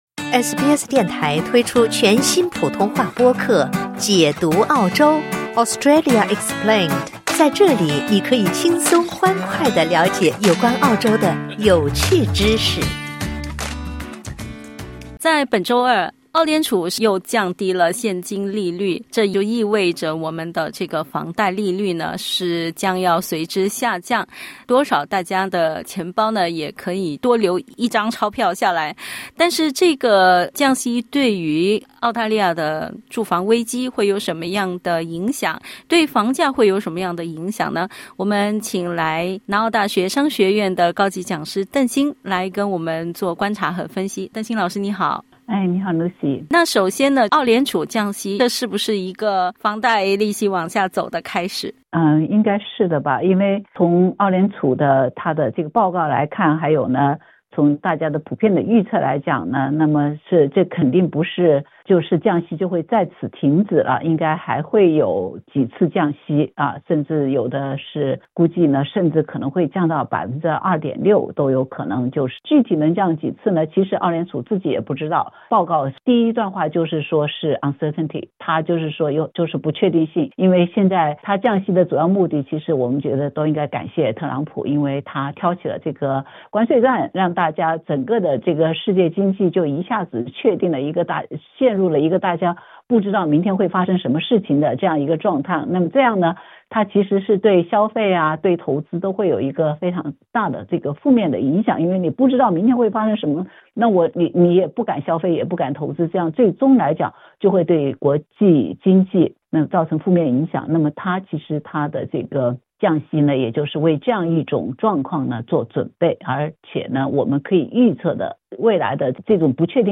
（点击音频收听详细采访） 澳大利亚储备银行（RBA）于周二下午宣布将利率下调0.25%至3.85%。